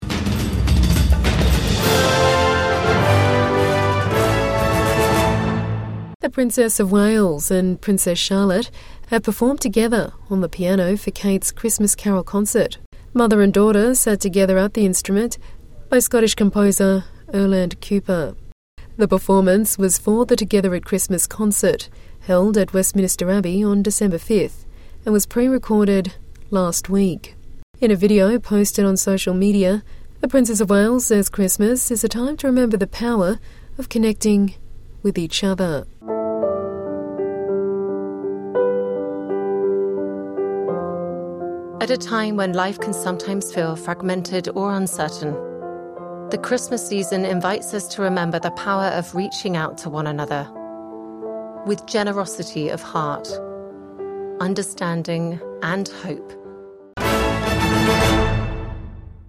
Princess of Wales and Princess Charlotte perform Christmas carol duet